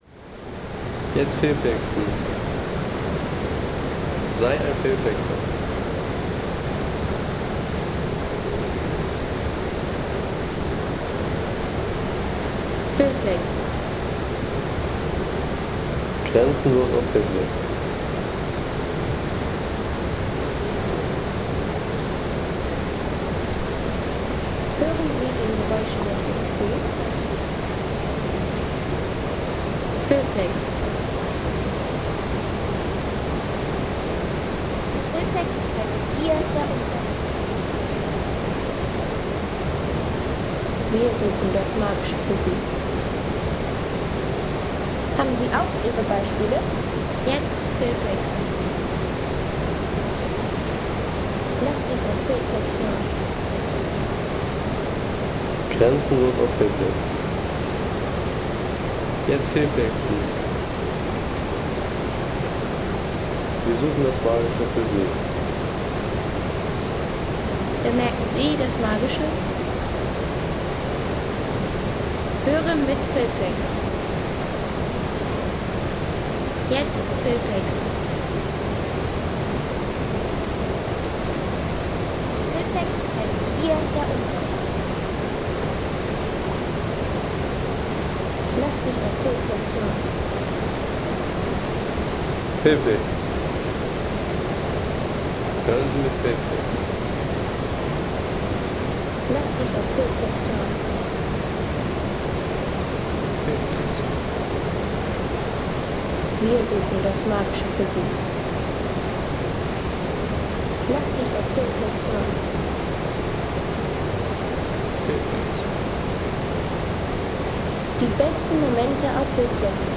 Untertalbach Home Sounds Landschaft Bäche/Seen Untertalbach Seien Sie der Erste, der dieses Produkt bewertet Artikelnummer: 203 Kategorien: Landschaft - Bäche/Seen Untertalbach Lade Sound.... Versteckter Waldabschnitt am 'Wilde Wasser'-Wanderweg.